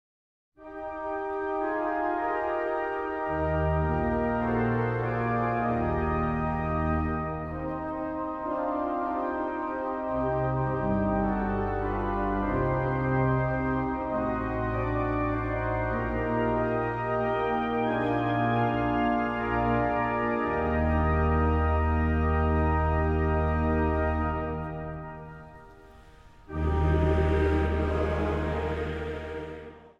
Zang | Mannenkoor